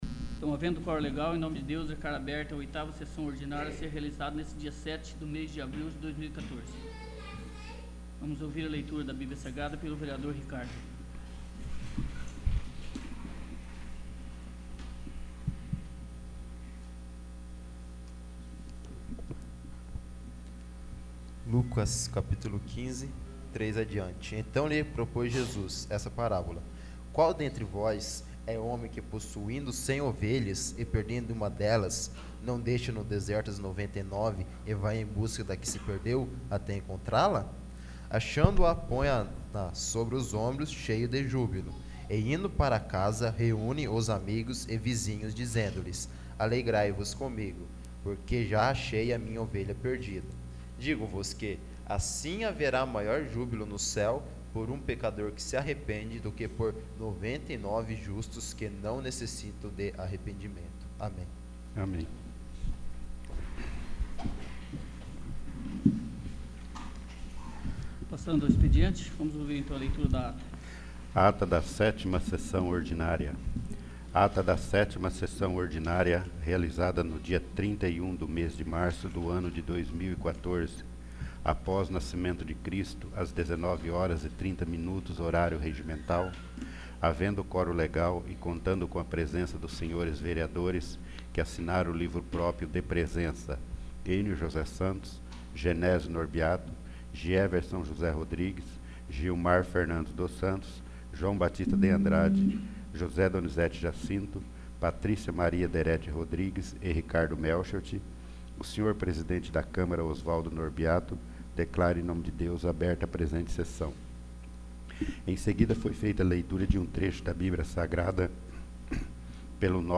8º. Sessão Ordinária